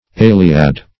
eyliad - definition of eyliad - synonyms, pronunciation, spelling from Free Dictionary Search Result for " eyliad" : The Collaborative International Dictionary of English v.0.48: Eyliad \Ey"li*ad\, n. See [OE]iliad .
eyliad.mp3